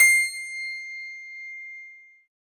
53s-pno21-C5.aif